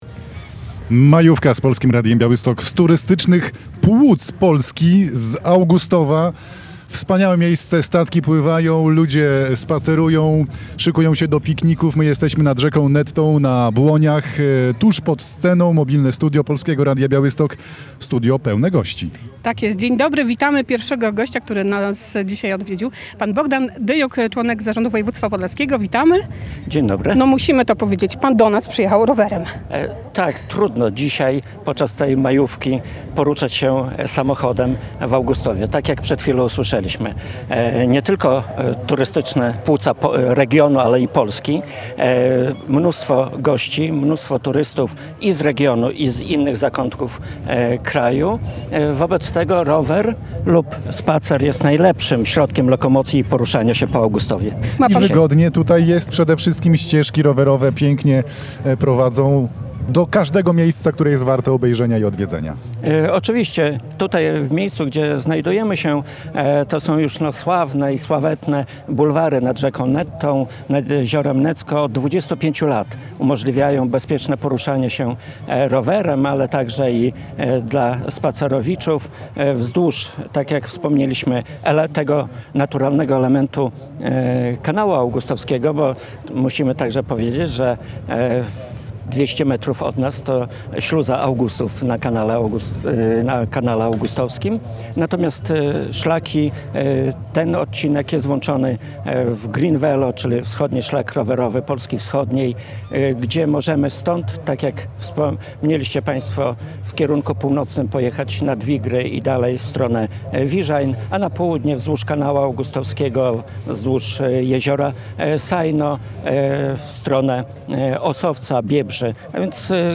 W piątek (2.05) Polskie Radio Białystok nadawało na żywo z serca letniej stolicy Polski, czyli Augustowa. Plenerowe studio trwało od 14:00 do 18:30 na Błoniach nad Nettą, a antenowy czas wypełnialiśmy ciekawymi konkursami i rozmowami z zaproszonymi gośćmi.